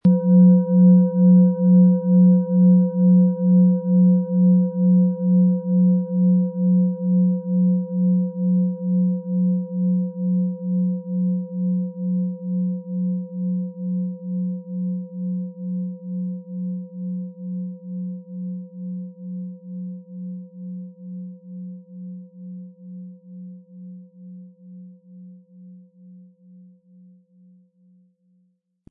Planetenton 1
Wasser
MaterialBronze